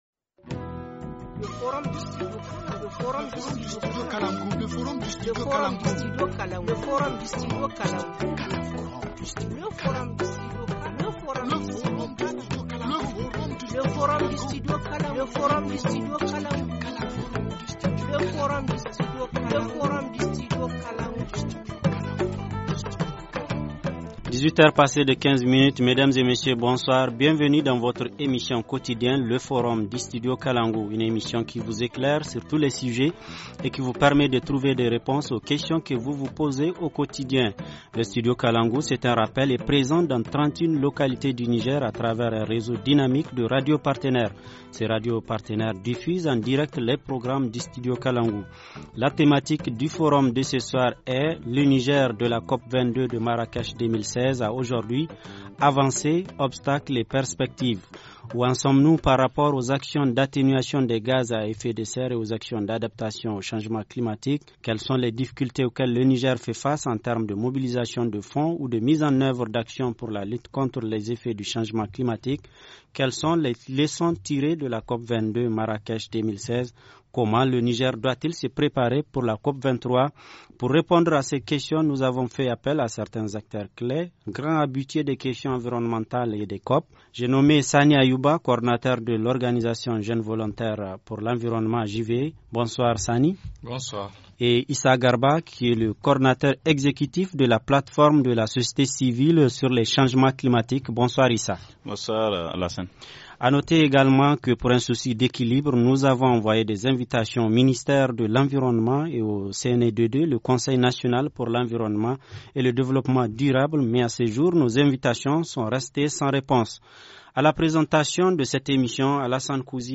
Pour répondre à ces questions nous avons fait appel à certains acteurs clés, grands habitués des questions environnementales et des COP